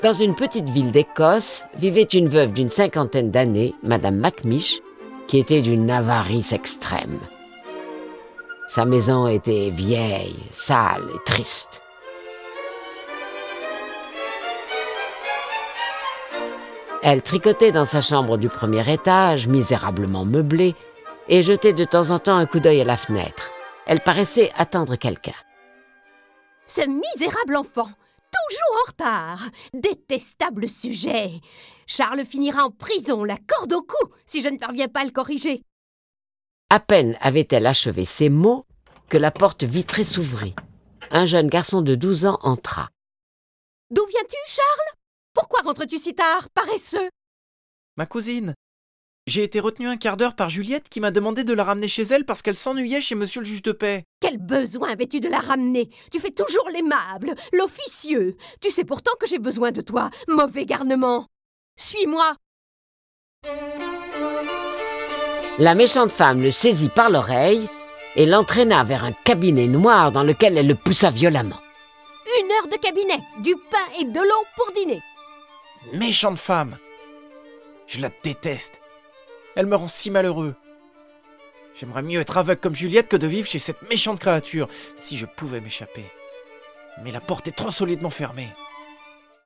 Cette version sonore des aventures du jeune Charles et de ses amis est animée par onze voix et accompagnée de près de trente morceaux de musique classique.
Le récit et les dialogues sont illustrés avec les musiques de Albinoni, Bizet, Brahms, Charpentier, Chopin, Delibes, Dvorak, Grieg, Haendel, Haydn, Locatelli, Marcello, Mozart, Pergolese, Tchaïkovksi, Telemann, Vivaldi.